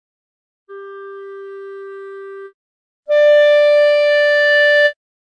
• il primo è debole;
• il secondo è forte.